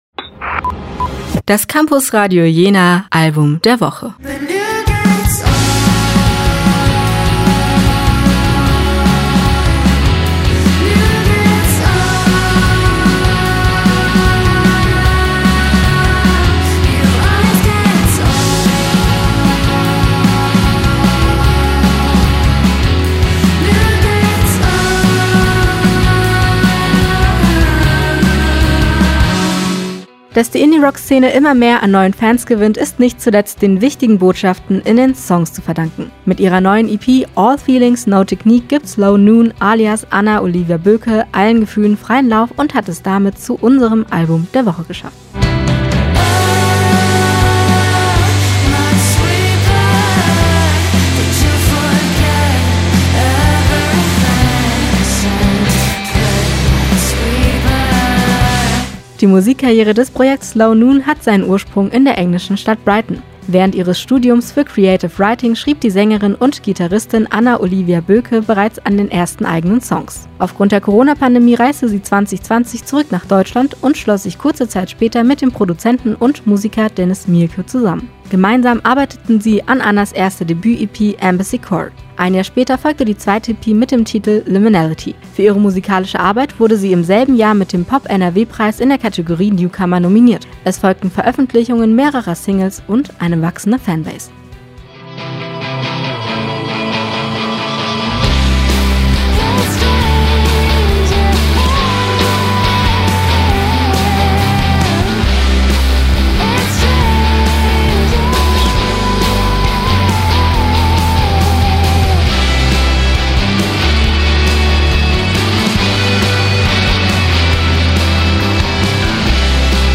Stimme & Schnitt